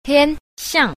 tian_xiang.mp3